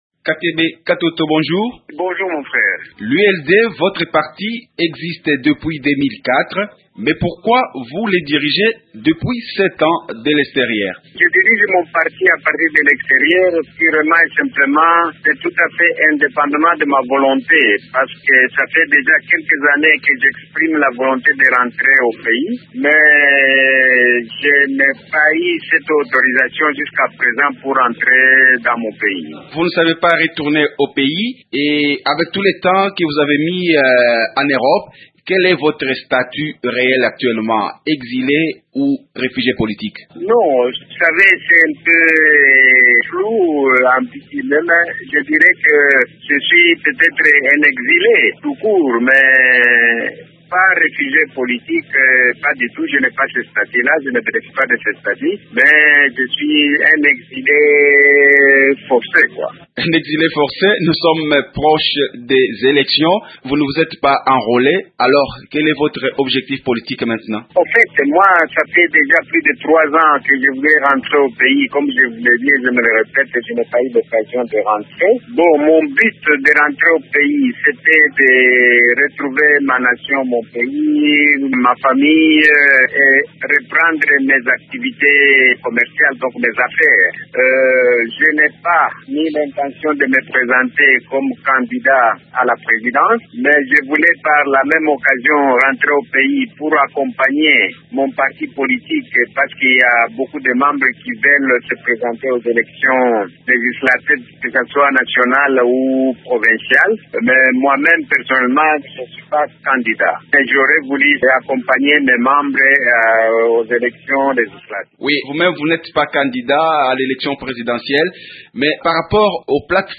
Le président national de l’Union des libéraux démocrates (ULD), Raphaël Katebe Katoto est l’invité de Radio Okapi. Son parti n’a pas signé des accords en perspective des élections avec d’autres partis politiques. Katebe Katoto indique qu’il n’est pas candidat aux prochaines élections mais que son parti va concourir.